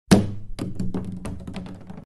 plasticpipe2.mp3